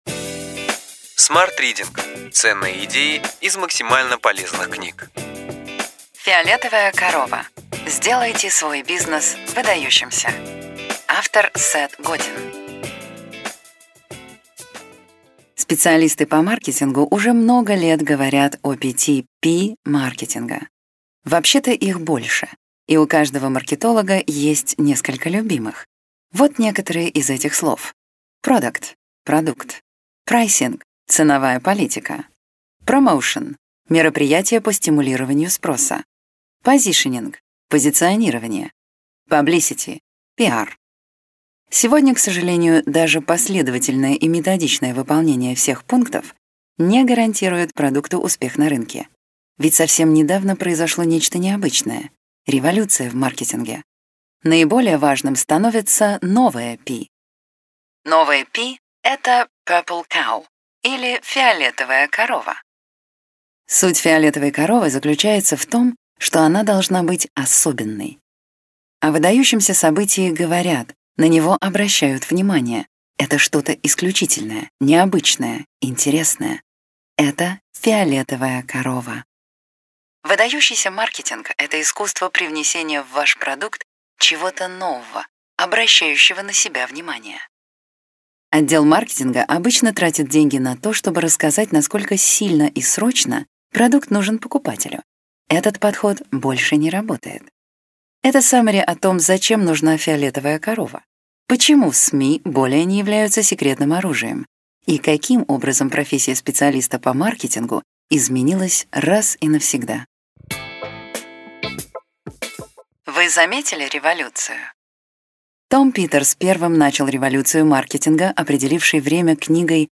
Аудиокнига Ключевые идеи книги: Фиолетовая корова. Сделайте свой бизнес выдающимся!